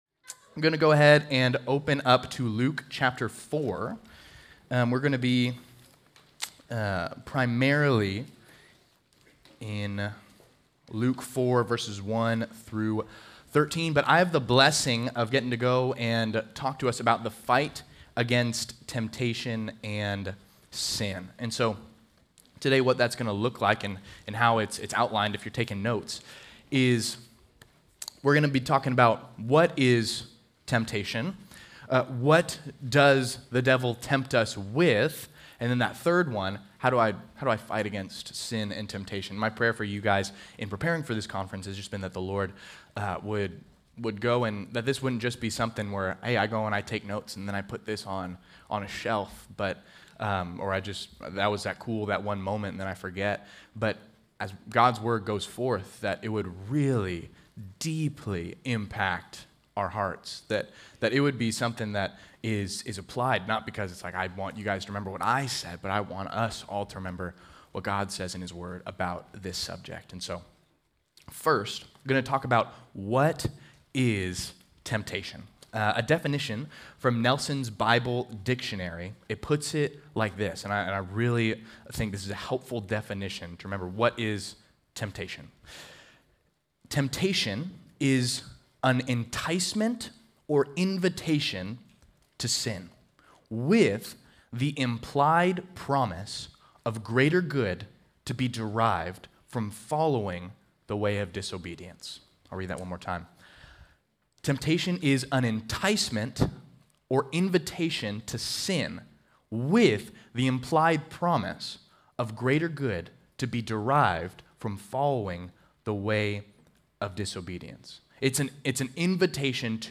Home » Sermons » The Fight Against Temptation